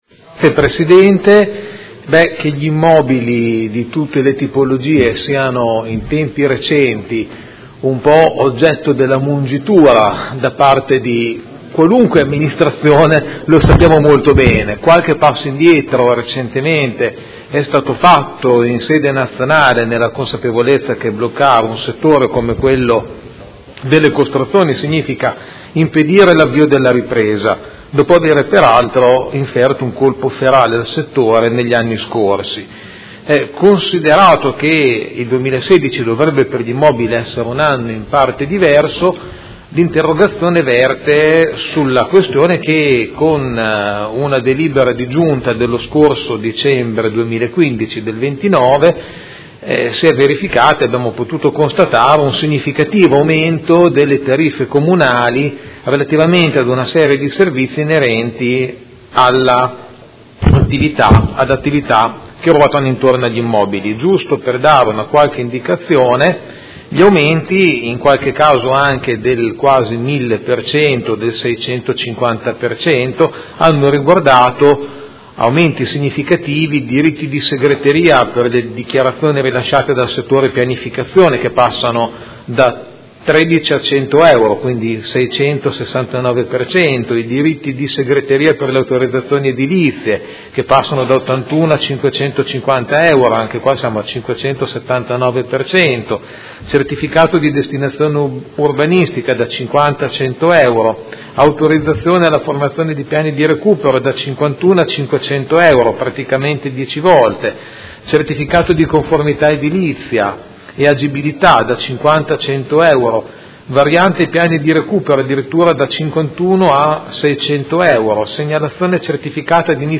Seduta del 10/03/2016. Interrogazione del Consigliere Pellacani (F.I.) avente per oggetto: Aumento tariffe comunali del Settore Urbanistico